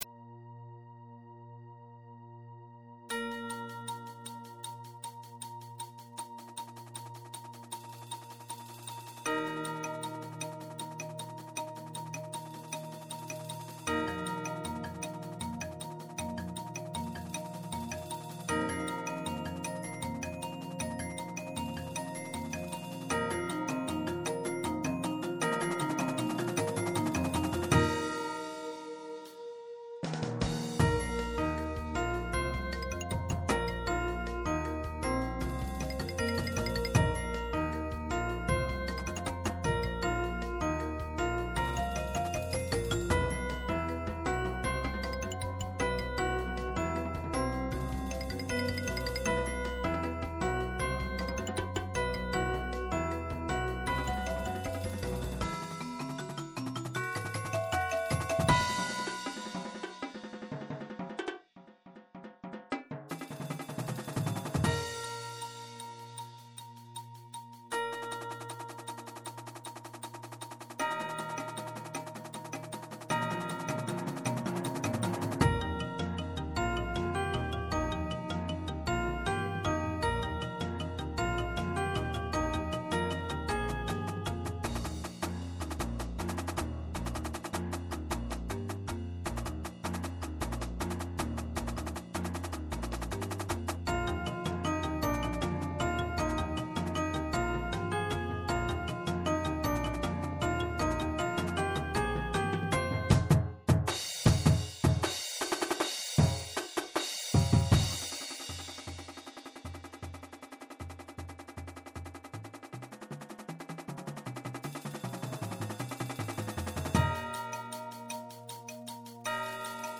Beginning (PSA)
Bells
Xylophone
Marimba (2)
Vibes
Chimes
Timpani
Synth (2)
Electric Bass
Drumset
Auxiliary Percussion
Snare
Tenors (quints)
Bass Drums (5)